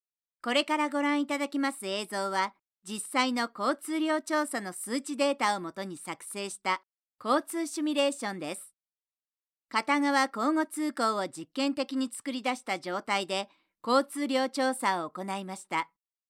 【ナレーターボイスサンプル】
ボイスサンプル4（落ち着いた・ビジネストーン）[↓DOWNLOAD]
声質は少しハスキーなところがあります。